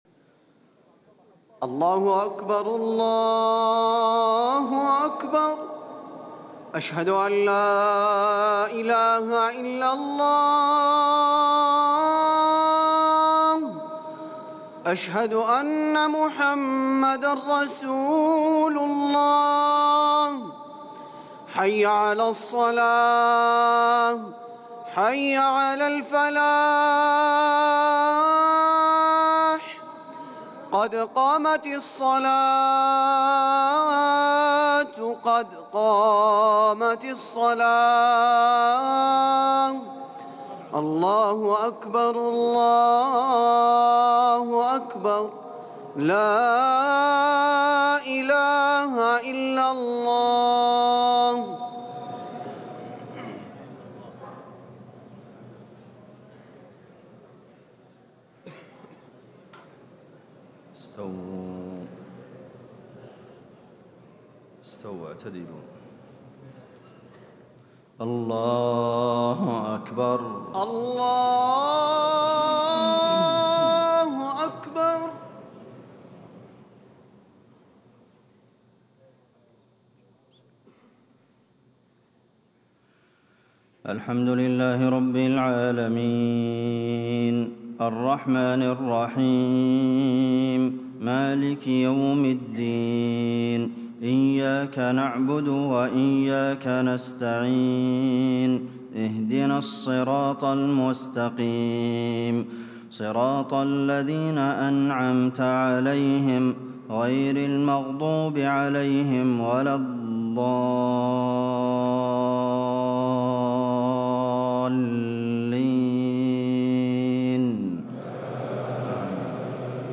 صلاة المغرب 1 جمادى الآخرة 1437هـ من سورة الأعراف 54-58 > 1437 🕌 > الفروض - تلاوات الحرمين